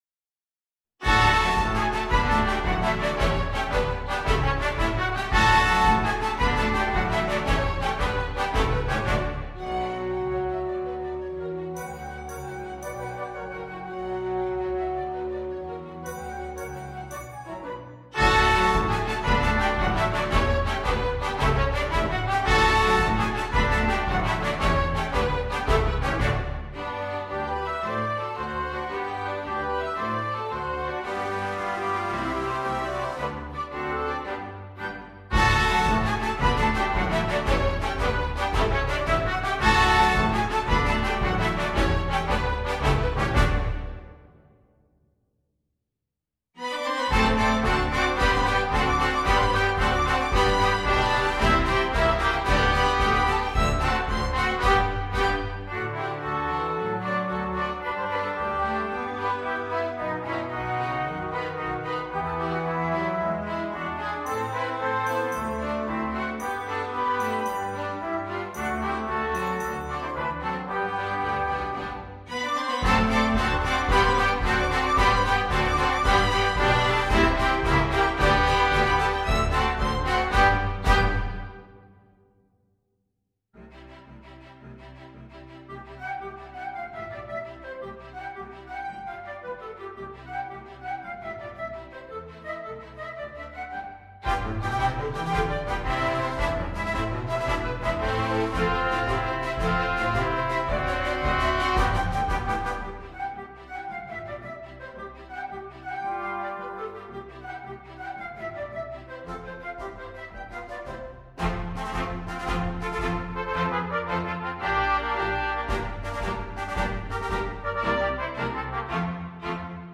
Title Prince Of Good Fellows Subtitle Dedication Composer Allen, Thomas S. Arranger Date 1902 Style Quadrille Instrumentation Salon Orchestra Score/Parts Download Audio File:Prince of Good Fellows.mp3 Notes